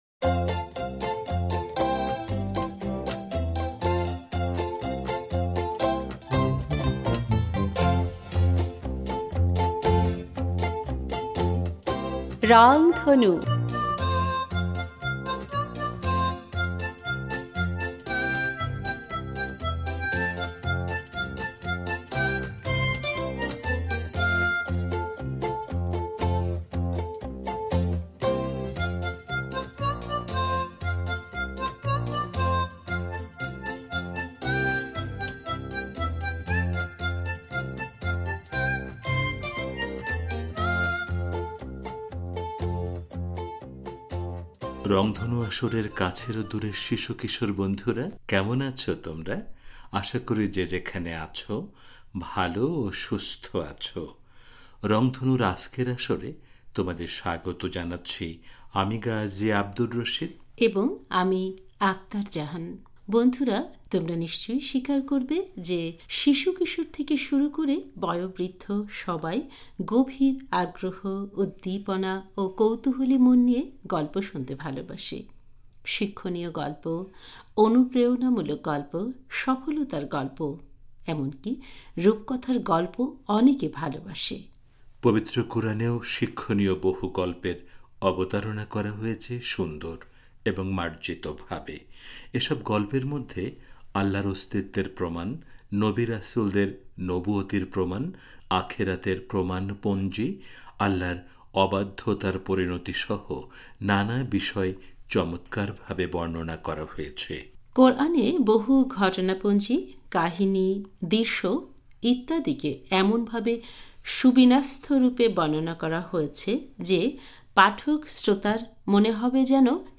আজকের আসরে আমরা হযরত সোলাইমান ও রানি বিলকিসের কাহিনিটি শোনাব। আর সবশেষে থাকবে একটি গান।